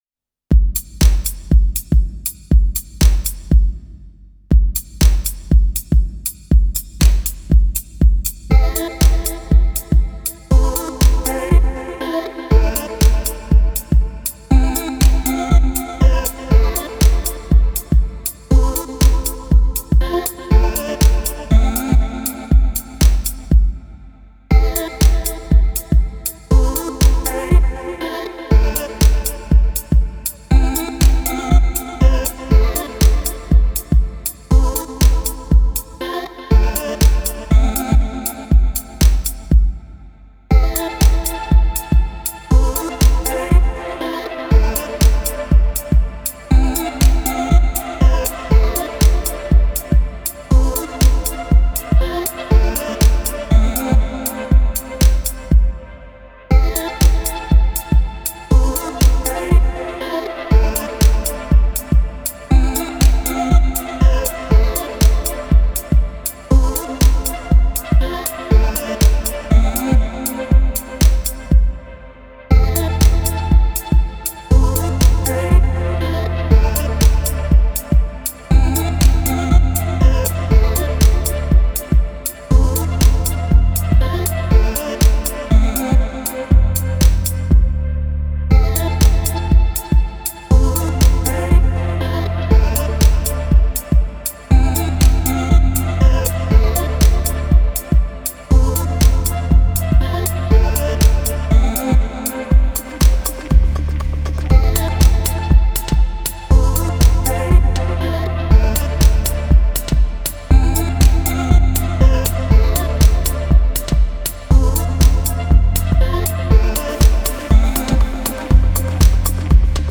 A la croisée de l'electronica et de la house music